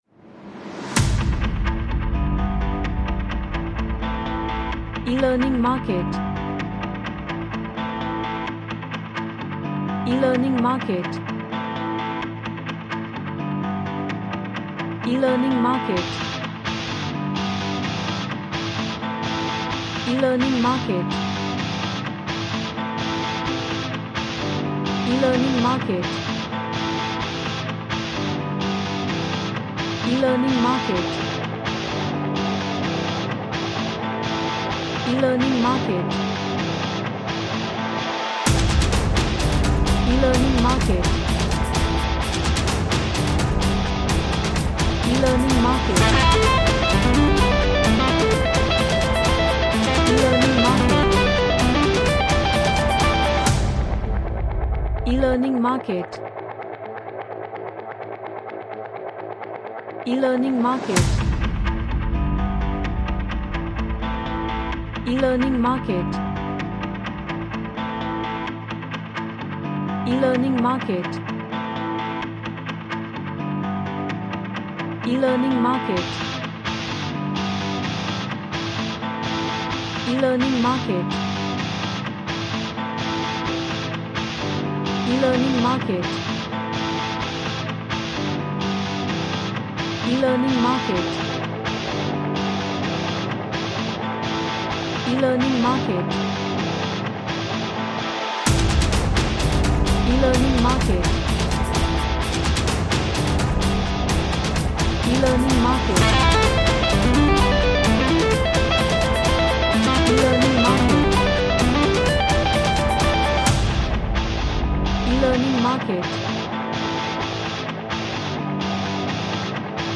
A heavy cinematic War music
Action / Sports